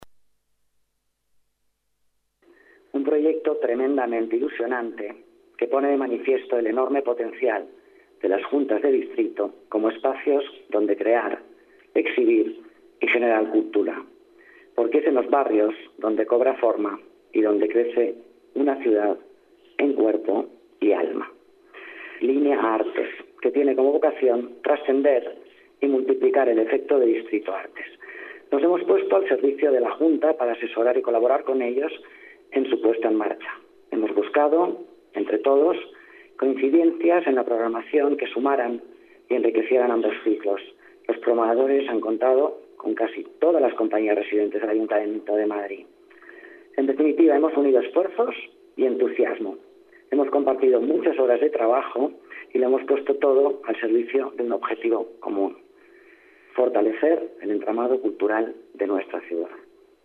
Nueva ventana:Declaraciones de Alicia Moreno en Línea Artes